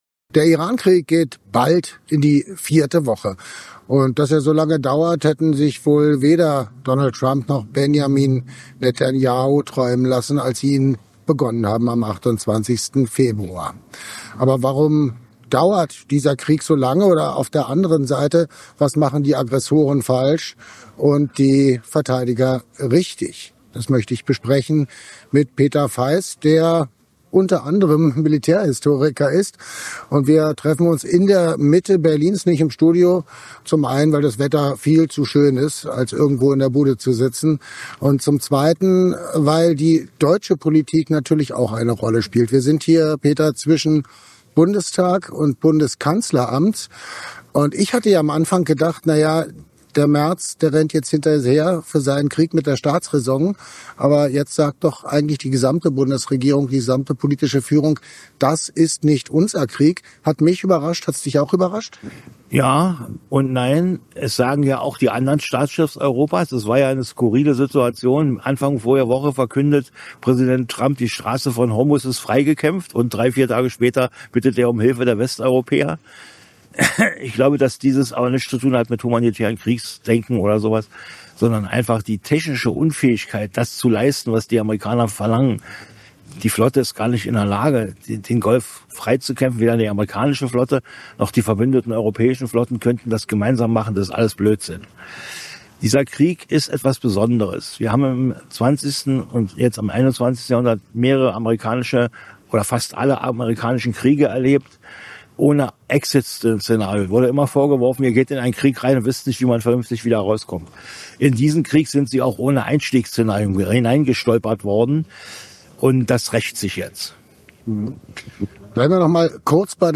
Warum führt US-Präsident Donald Trump Krieg gegen den Iran? Und wie ist der Konflikt militärisch einzuordnen? Brisantes Interview